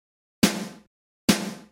冥想手鼓的节奏
描述：简单的手鼓节奏。
Tag: 140 bpm Electronic Loops Drum Loops 295.49 KB wav Key : Unknown